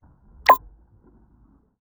UI_SFX_Pack_61_32.wav